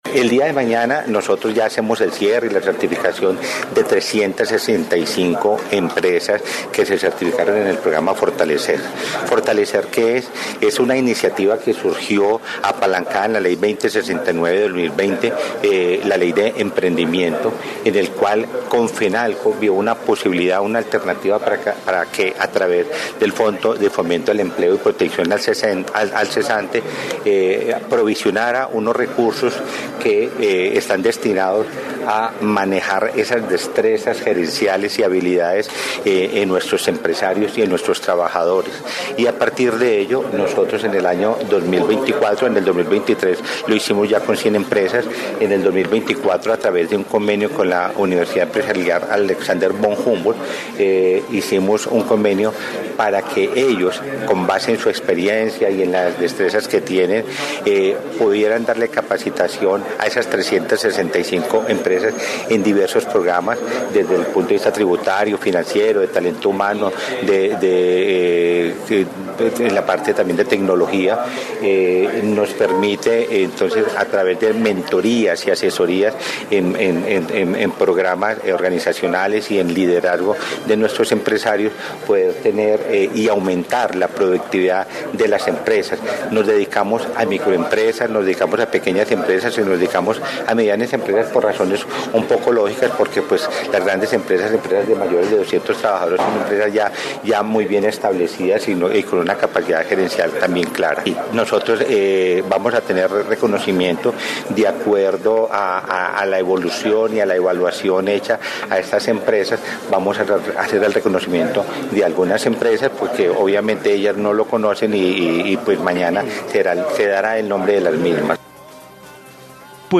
Informe empresarios Quindío